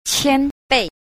9. 千倍 – qiān bèi – thiên bội (nghìn lần)
qian_bei.mp3